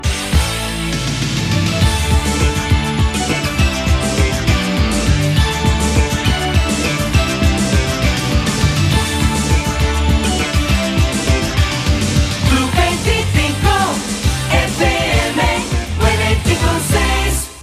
c8d023dab492cb3410be306ae91bb4f115c7fd44.mp3 Títol Radio Club 25 Emissora Radio Club 25 Cadena Radio Club 25 Titularitat Privada local Descripció Indicatiu de l'emissora als 95.6 MHz.